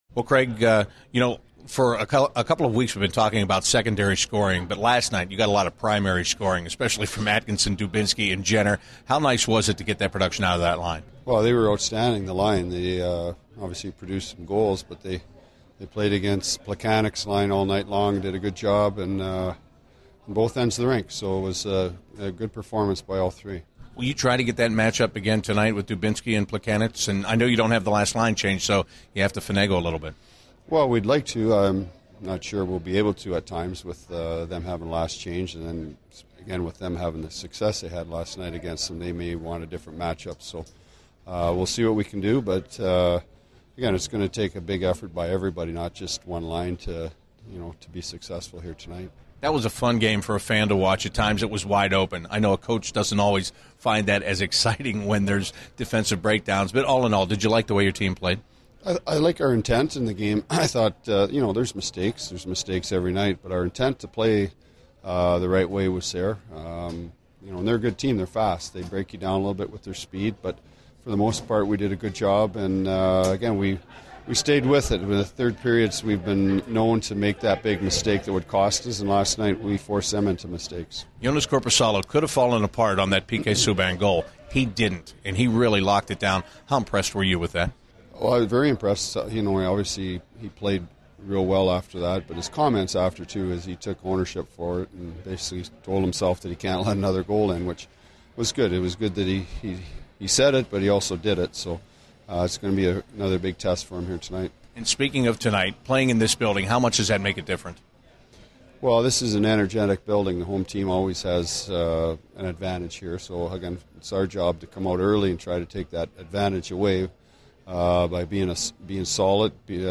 An episode by CBJ Interviews